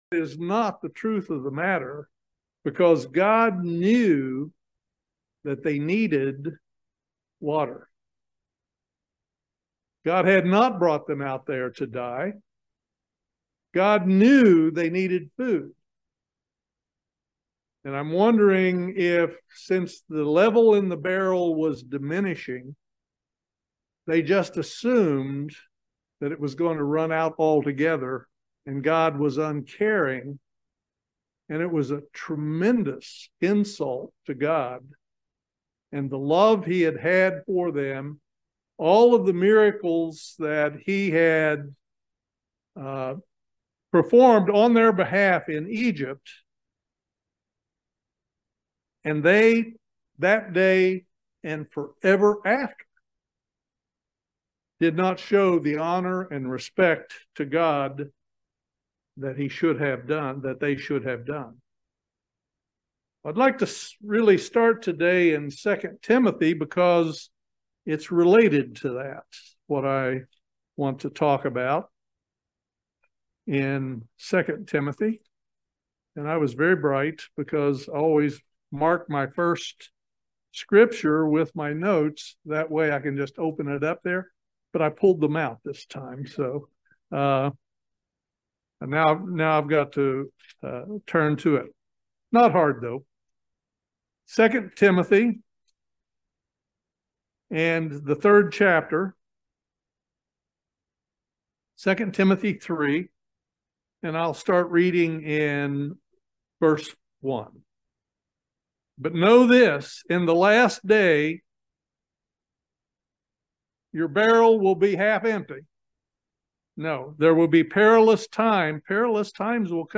Join us for this video sermon on the subject of respect for God. Do we show the proper honor and respect for God?
Given in Lexington, KY